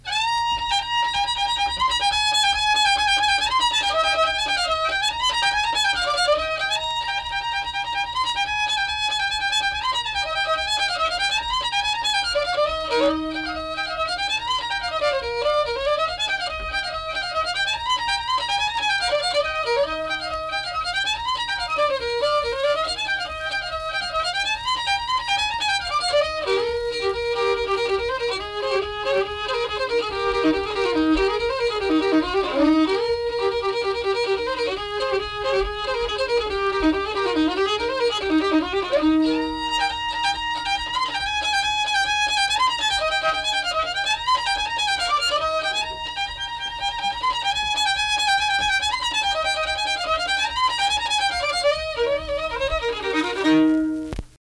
Unaccompanied fiddle music and accompanied (guitar) vocal music
Instrumental Music
Fiddle
Braxton County (W. Va.)